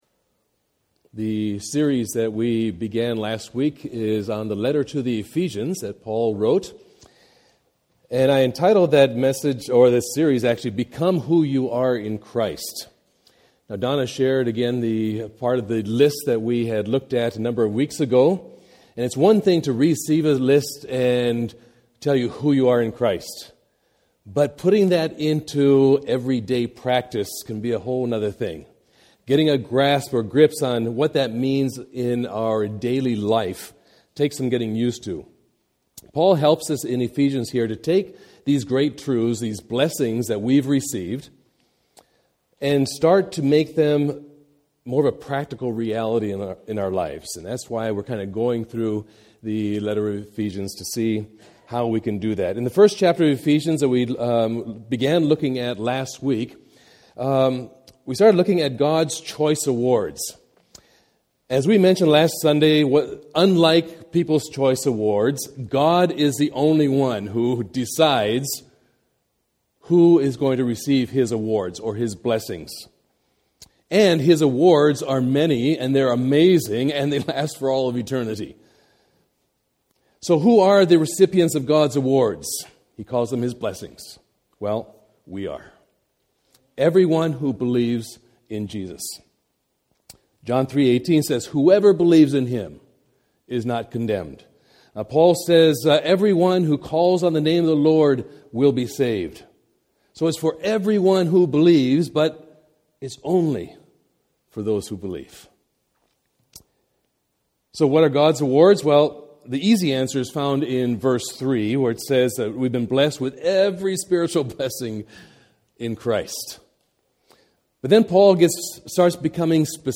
God’s Choice Awards (Part 2) – Scio Community Church